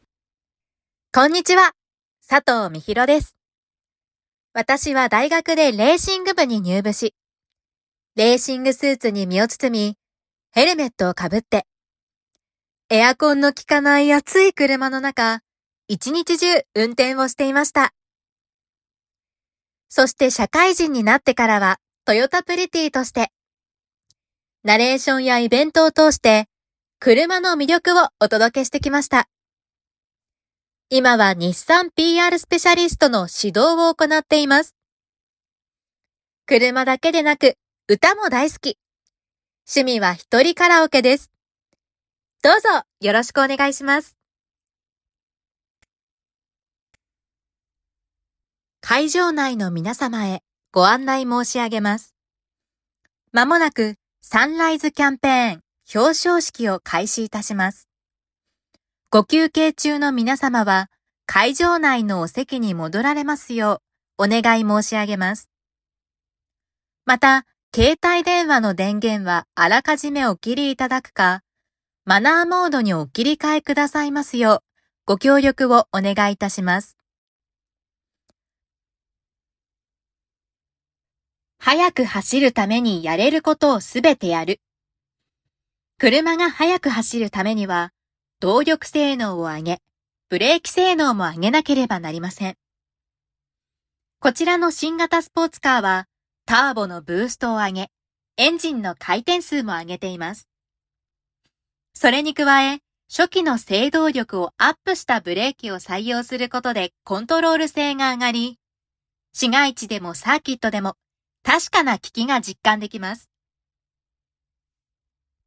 ナレーター｜MC｜リポーター